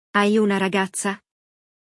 No episódio de hoje, você vai ouvir um diálogo entre amigos onde um deles fala sobre sua nova namorada, descrevendo sua aparência e personalidade.